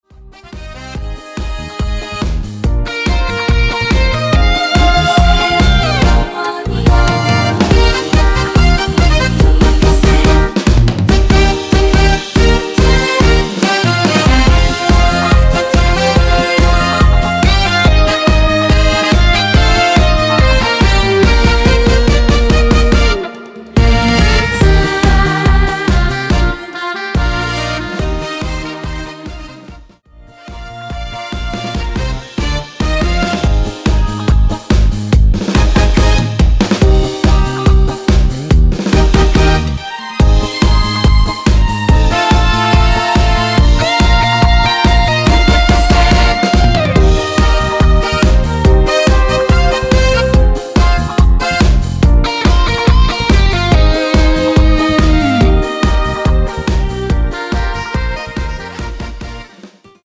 원키 코러스 포함된 MR입니다.
Eb
앞부분30초, 뒷부분30초씩 편집해서 올려 드리고 있습니다.